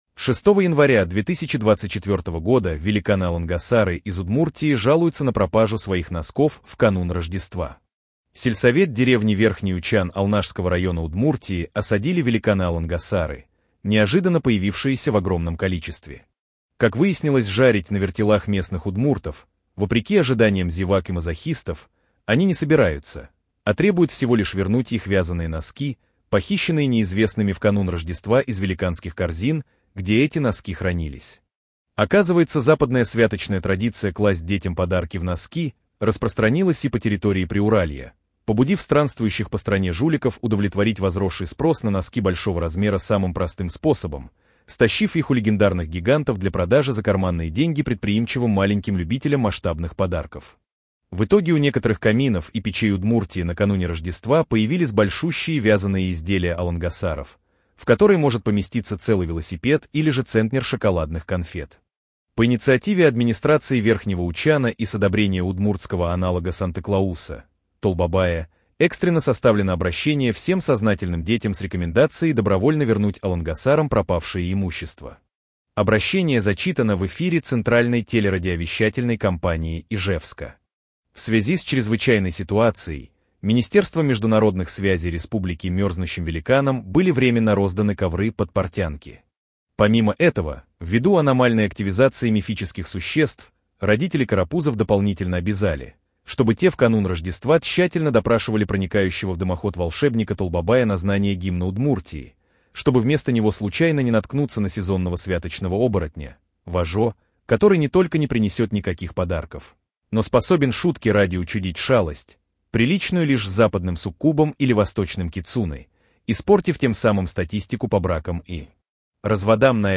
Голос «Герман», синтез речи нейросетью